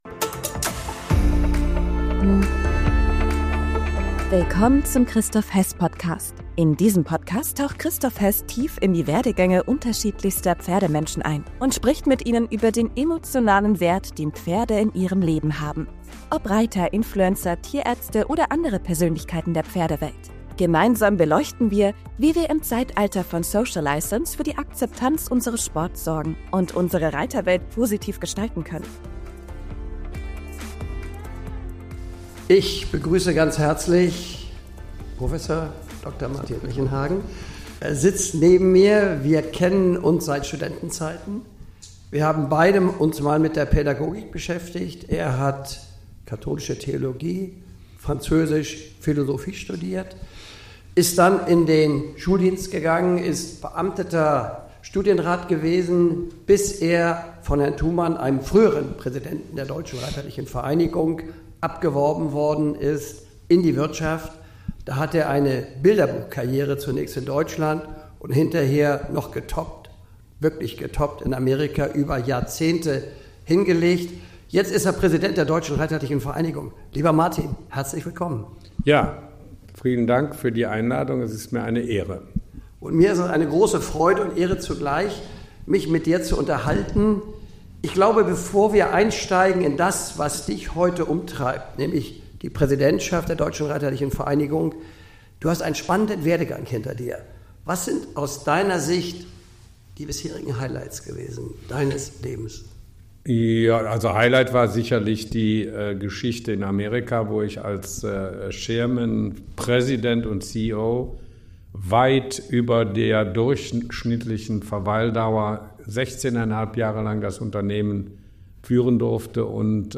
Ein spannendes, persönliches Gespräch voller Einblicke – nicht nur für Reiter!